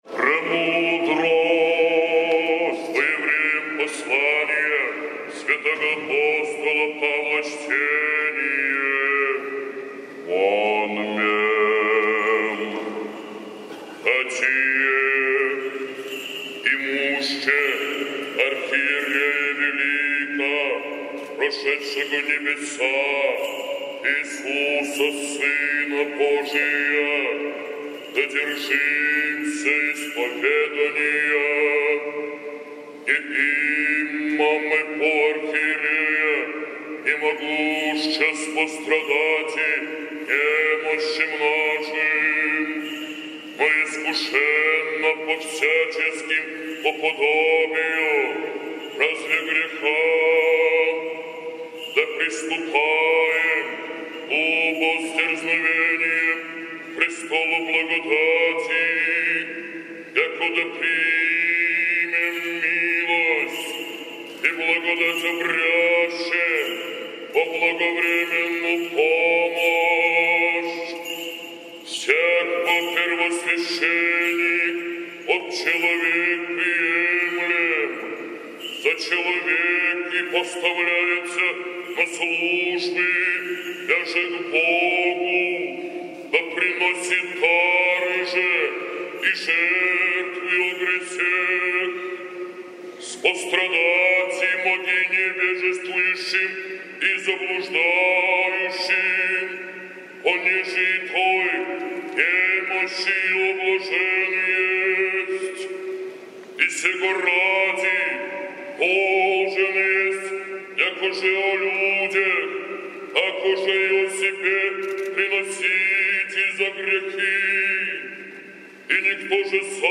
апостольское ЧТЕНИЕ